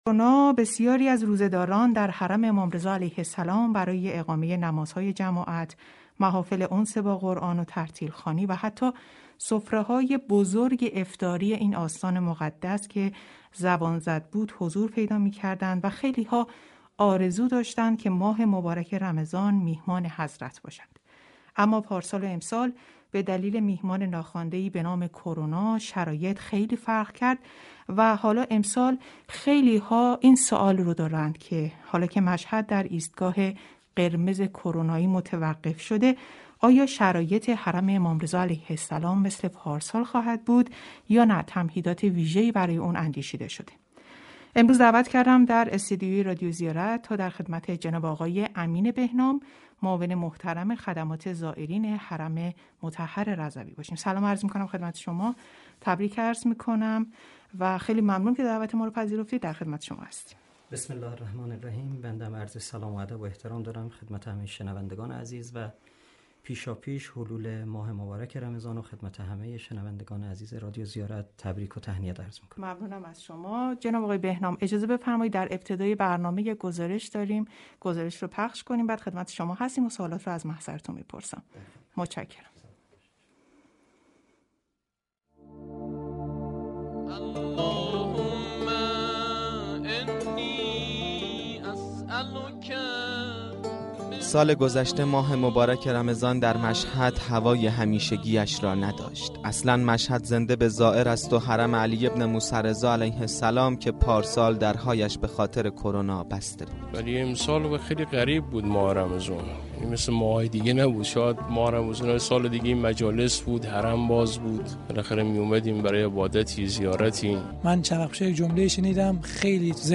گفتگوی ویژه خبری